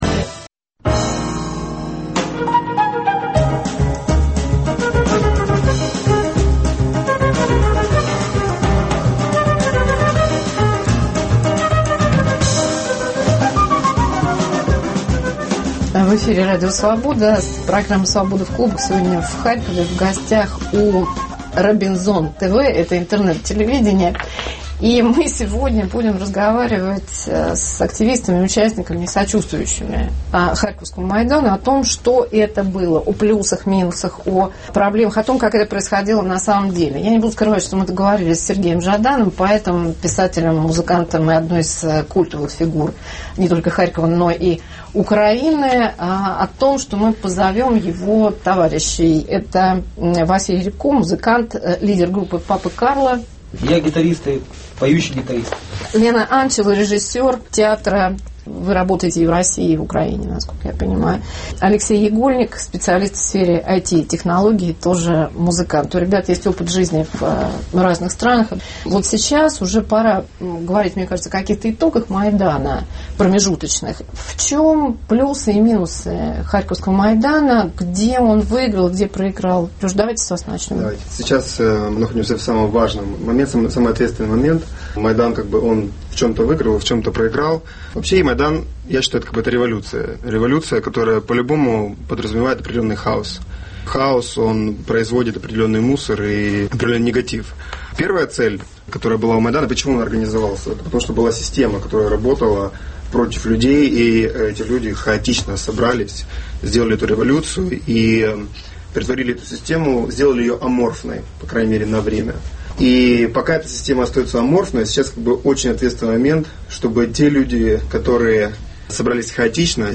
Программа записана накануне пророссйиских волнений в Харькове.